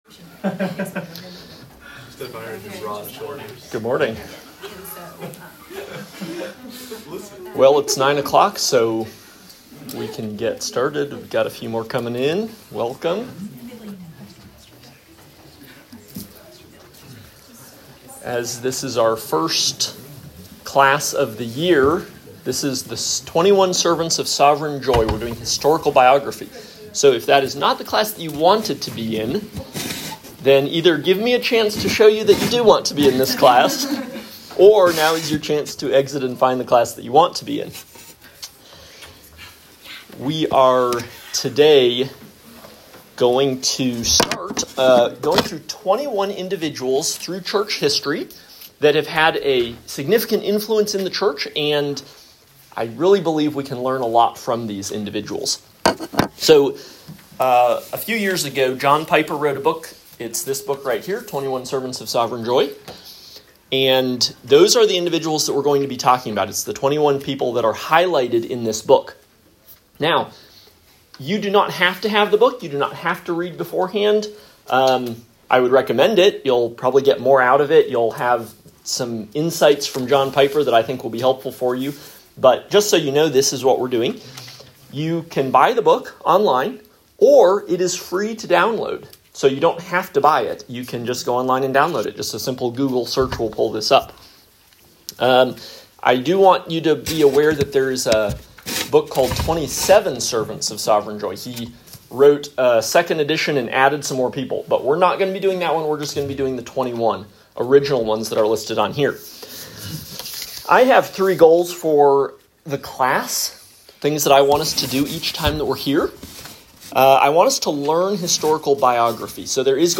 Lecture 1 – Athanasius
Lecture-1-Athanasius.m4a